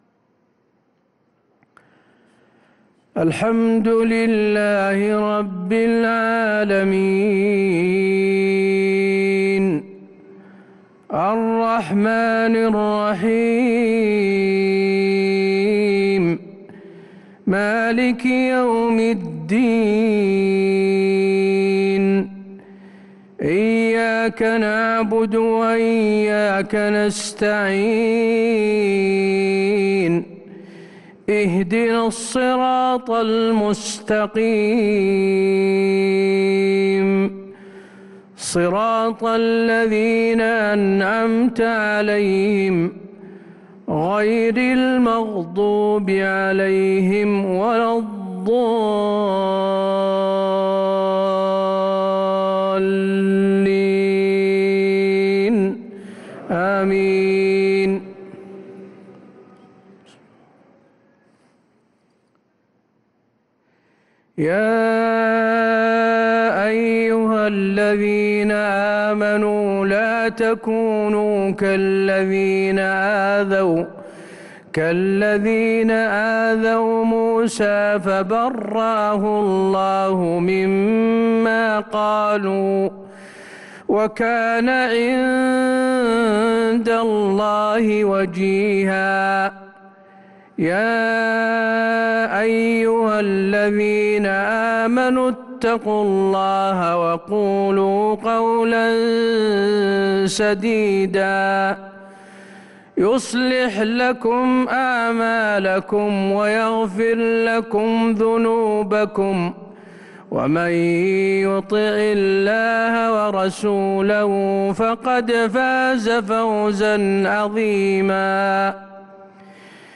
صلاة العشاء للقارئ حسين آل الشيخ 22 شوال 1443 هـ